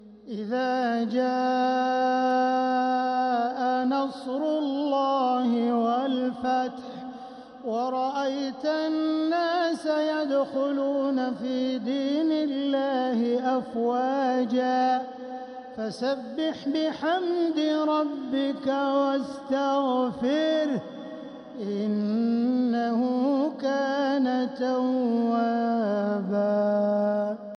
سورة النصر | مصحف تراويح الحرم المكي عام 1446هـ > مصحف تراويح الحرم المكي عام 1446هـ > المصحف - تلاوات الحرمين